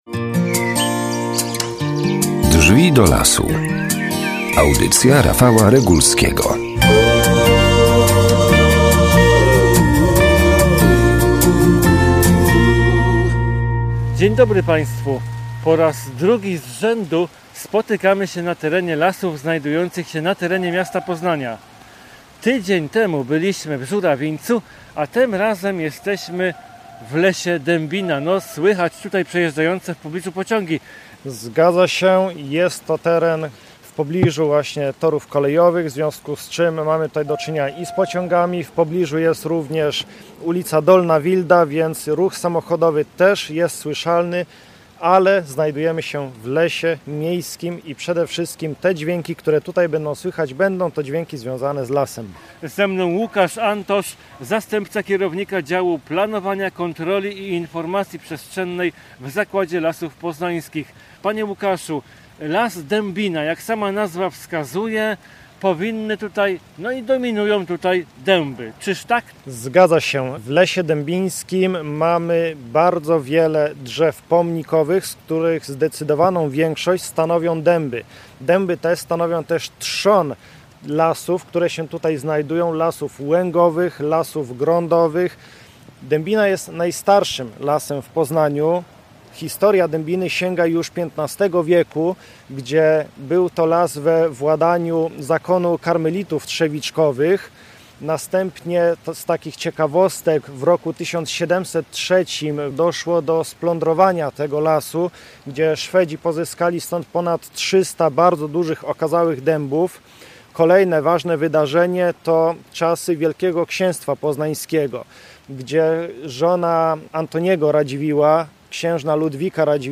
Tak samo jak tydzień temu dzisiejszy leśny spacer odbędziemy w… Poznaniu.